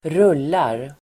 Uttal: [²r'ul:ar]